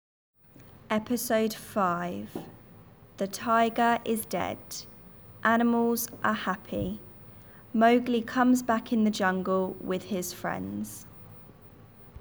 Episode 5 lent